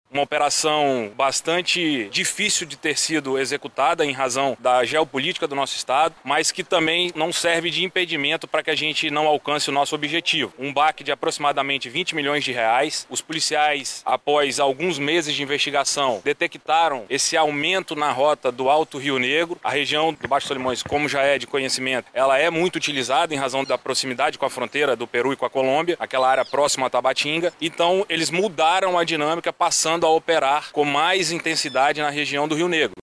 O delegado-geral da Polícia Civil do Amazonas, Bruno Fraga, destaca que a Polícia passou a atuar mais intensamente na região do Rio Negro devido à logística do narcotráfico.